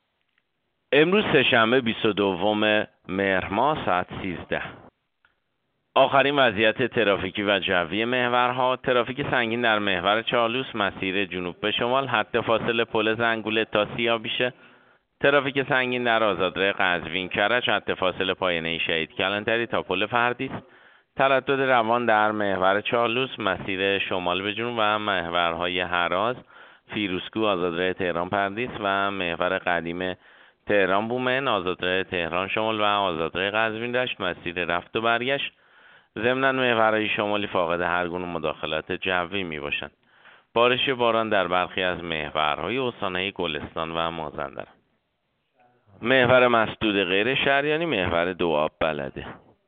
گزارش رادیو اینترنتی از آخرین وضعیت ترافیکی جاده‌ها ساعت ۱۳ بیست‌ودوم مهر؛